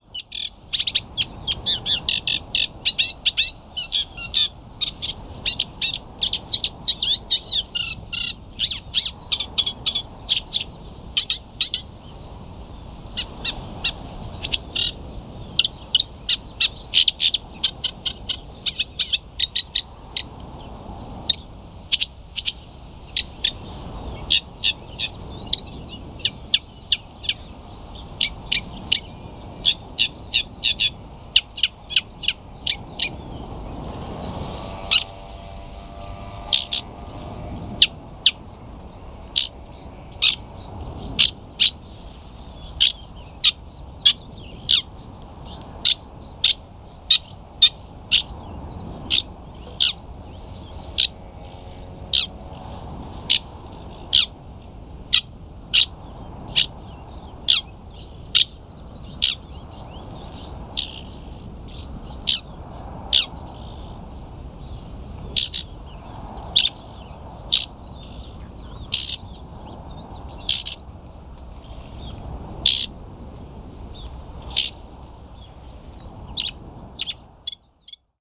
The fen was full of bird song, including a cuckoo, and numerous reed buntings, sedge warblers and reed warblers. Reed warblers have a rather frenetic and indignant song, as you can hear from the recording below.